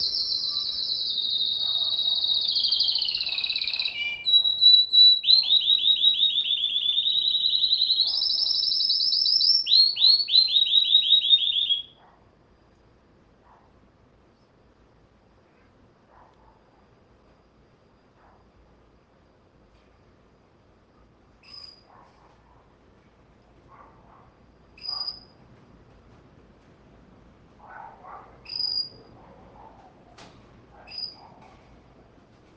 Sonidos del Pura Vida | BioSonidos - Colección de Sonidos de la Naturaleza